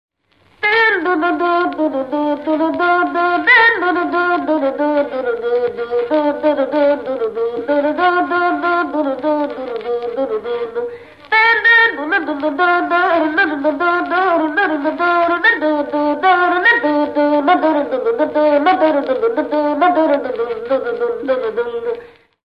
Műfaj Dudautánzás
Helység Szany